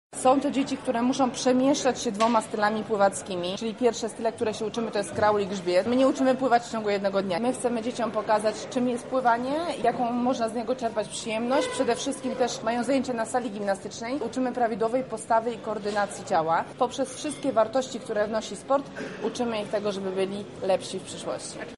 Więcej na ten temat mówi Otylia Jędrzejczak, pomysłodawczyni programu: